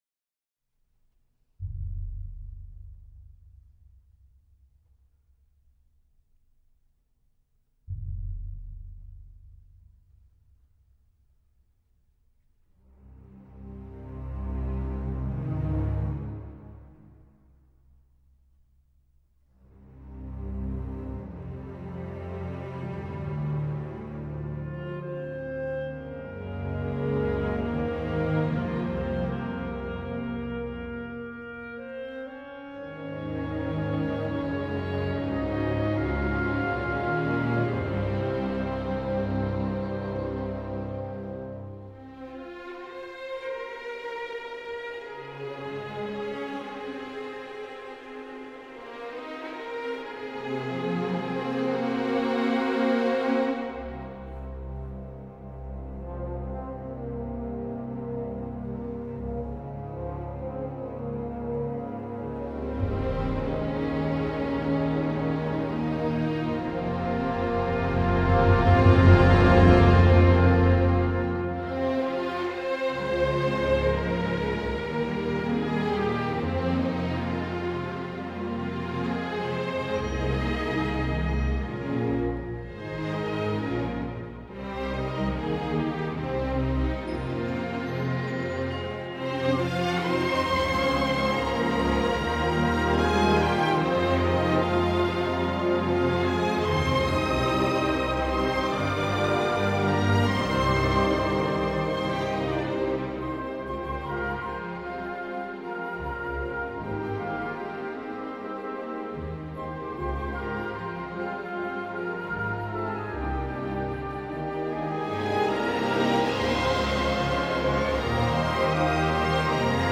很久没听这种没人唱的音乐，今天总数没白听，非常棒